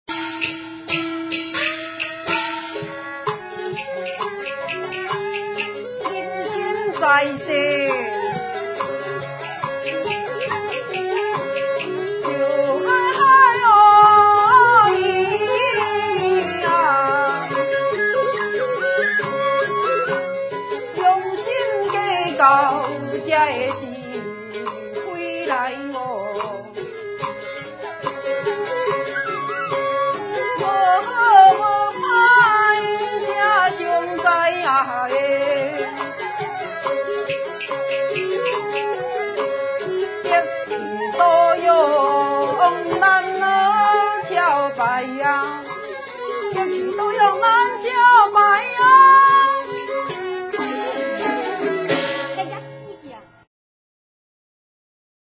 這裡收錄民間藝人的採集樣品，有歌仔調，有部分北管戲曲，也有通俗音樂（八音譜）等，都是鄉土原味。